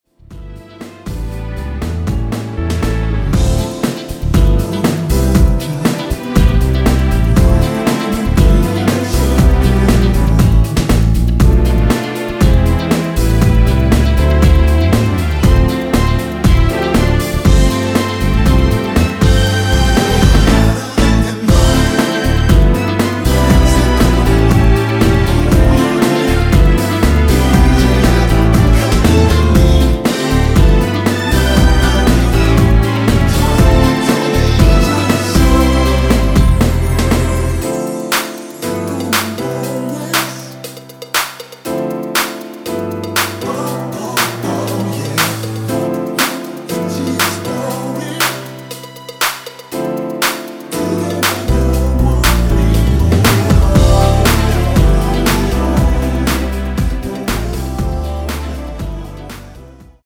원키에서(-2)내린 코러스 포함된 MR입니다.
앞부분30초, 뒷부분30초씩 편집해서 올려 드리고 있습니다.
중간에 음이 끈어지고 다시 나오는 이유는